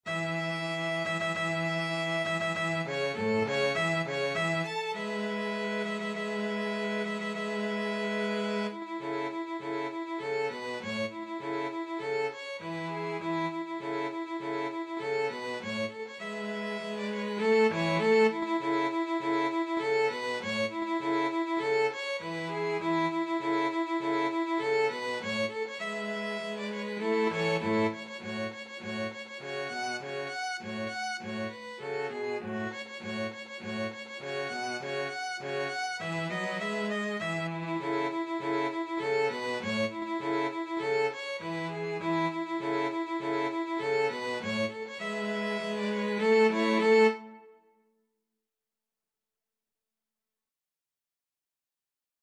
Violin 1Violin 2Cello
4/4 (View more 4/4 Music)
Presto =200 (View more music marked Presto)
Classical (View more Classical 2-Violins-Cello Music)